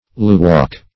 luwack - definition of luwack - synonyms, pronunciation, spelling from Free Dictionary
luwack - definition of luwack - synonyms, pronunciation, spelling from Free Dictionary Search Result for " luwack" : The Collaborative International Dictionary of English v.0.48: Luwack \Lu*wack"\, n. (Zool.)